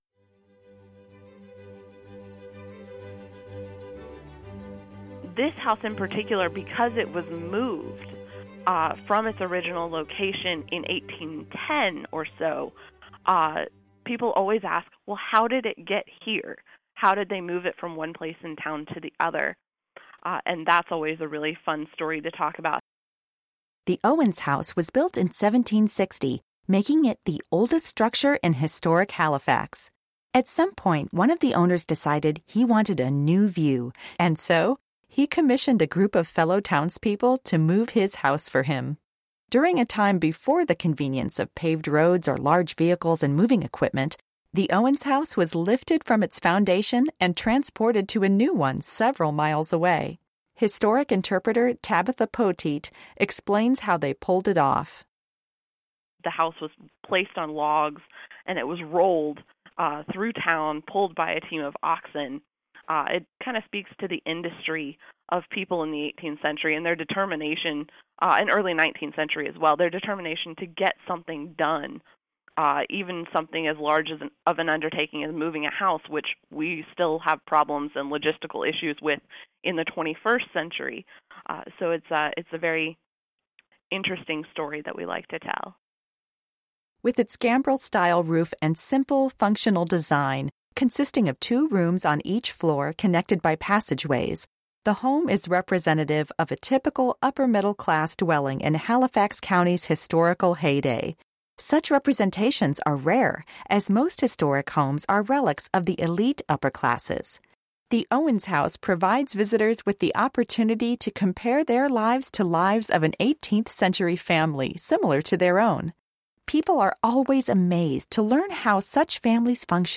Owens House - Audio Tour